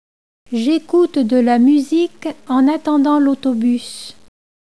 > Authentic native French audio recordings
Audio - French Optimnem French includes hundreds of authentic, native-spoken French audio recordings.